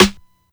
Snares
Sn (Grammys).wav